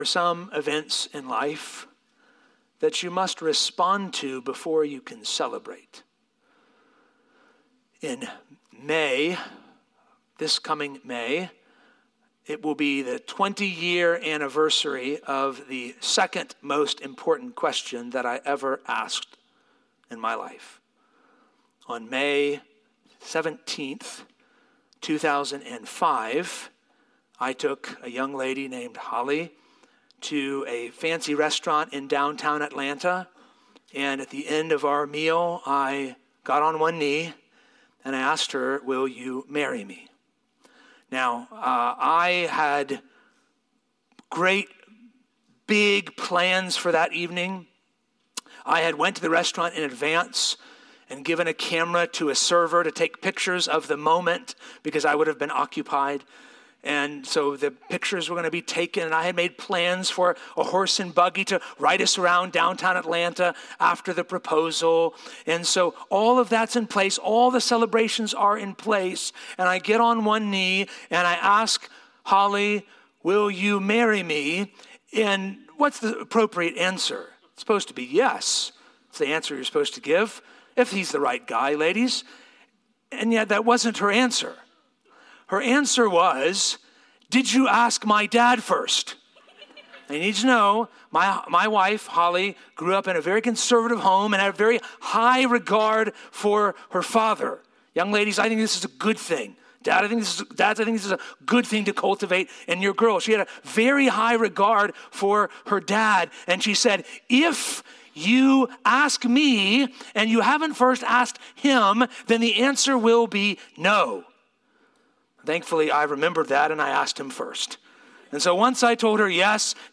Sermons | Poquoson Baptist Church